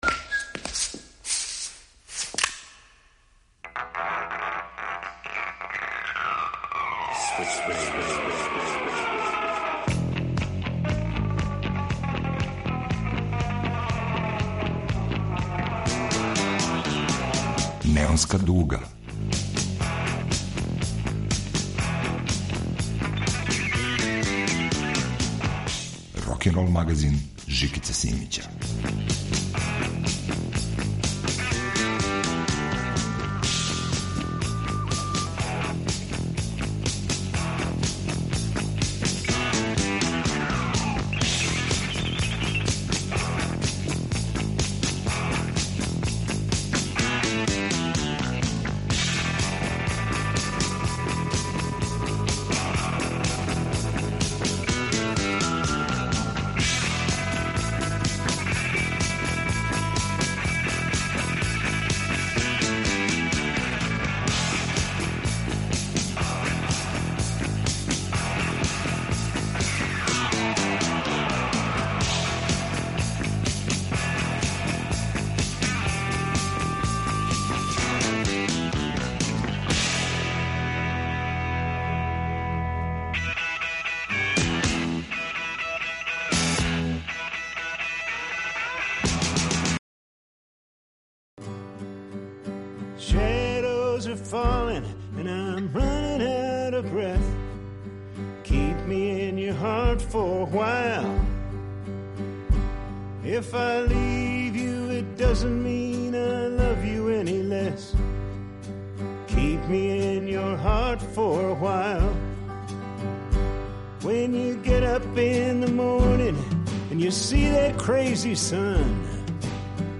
рокенрол магазин